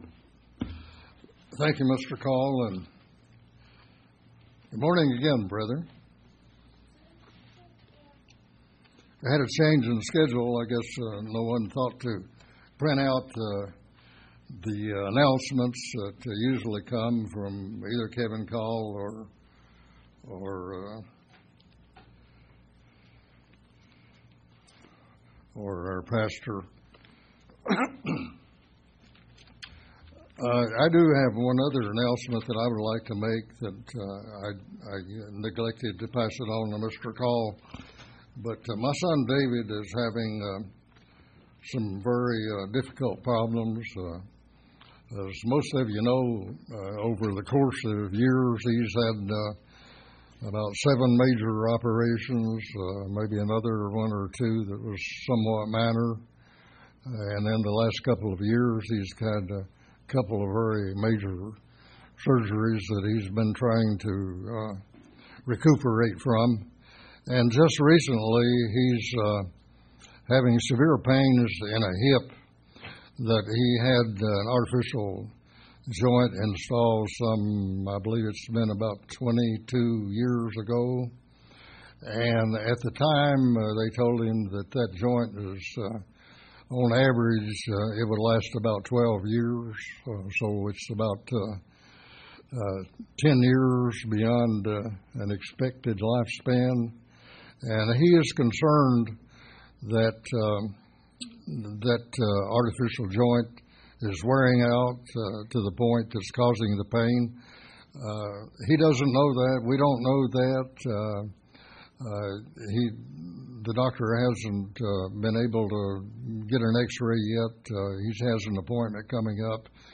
This sermon brings us the importance of God's promises and the never ending faith and belief in God and Jesus Christ. We must never forget the promises of God.